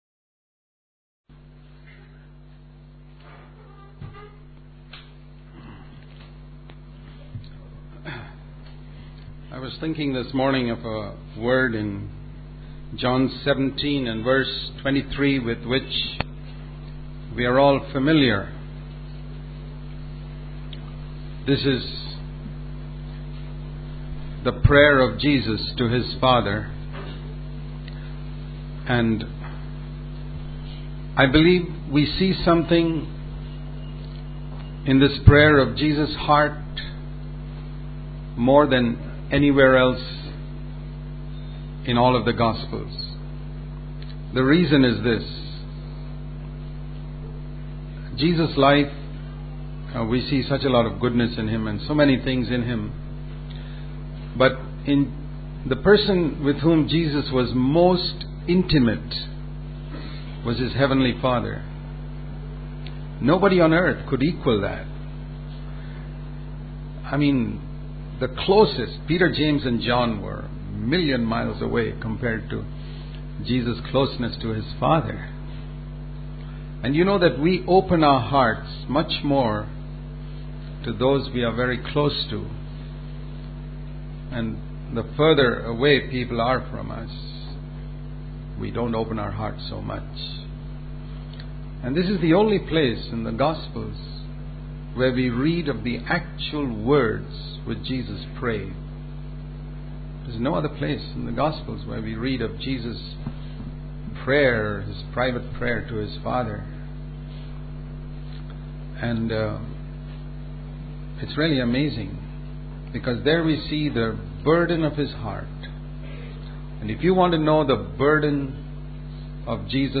In this sermon, the speaker emphasizes the goodness of God and the importance of believing in His goodness. He shares the story of Polycarp, who served God for 86 years and testified that God had only done good to him. The speaker also discusses the concept of being born again and compares it to a full-term baby who is healthy and eager for milk.